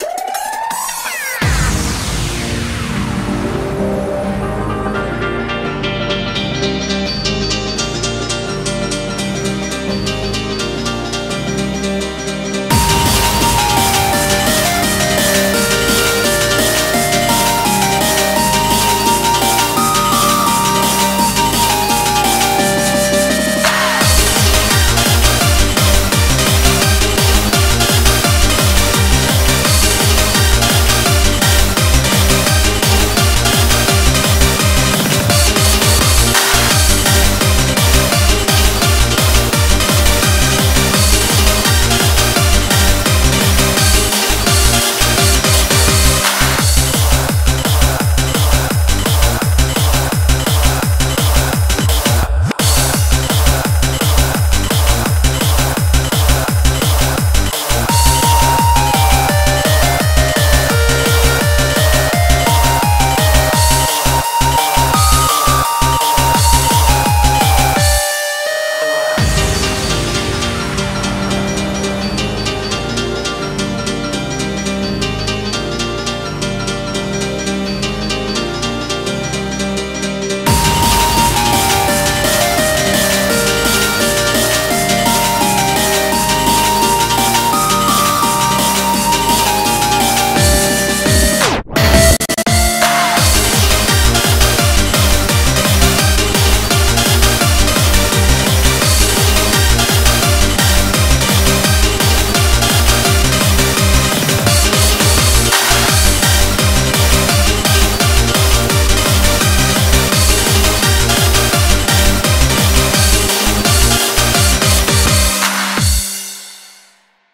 BPM170
Comments[HARDCORE]